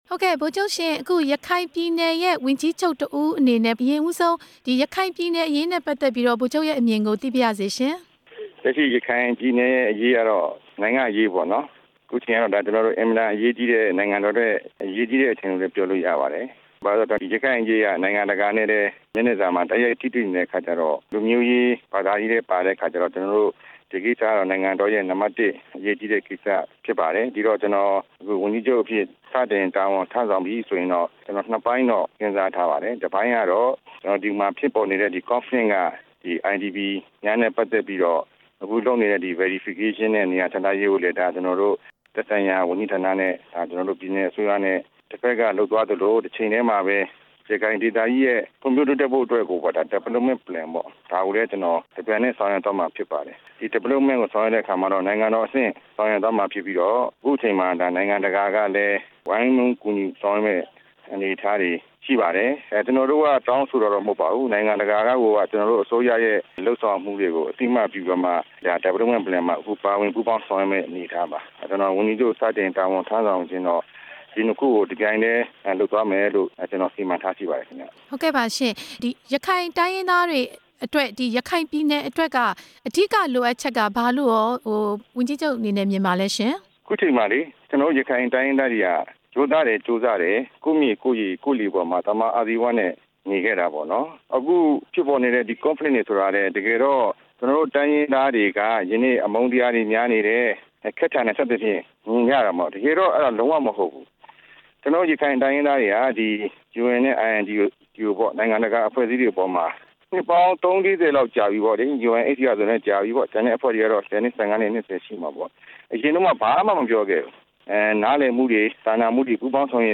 ဝန်ကြီးချုပ်အသစ် ဦးမောင်မောင်အုန်းနဲ့ မေးမြန်းချက်